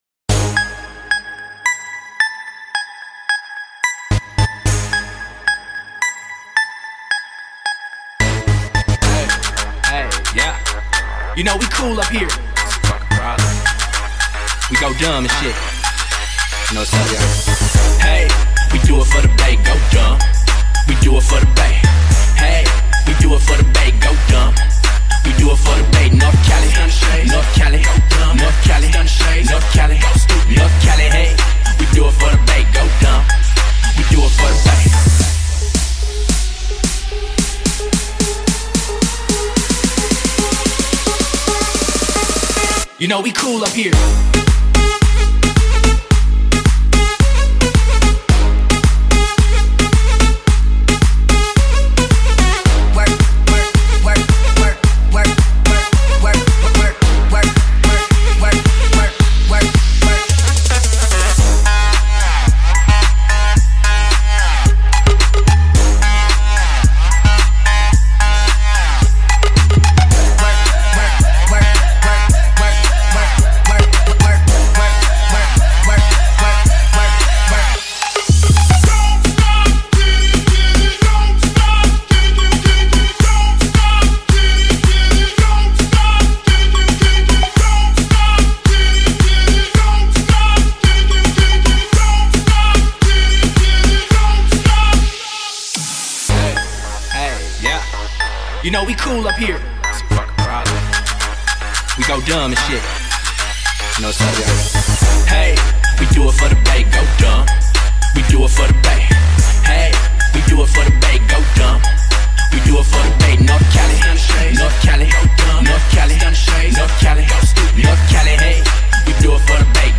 Bass Boosted Trap & Club Mix
Heavy drops, deep bass & pure adrenaline – feel the beat